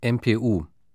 Aussprache:
🔉[ɛmpeːˈʔuː]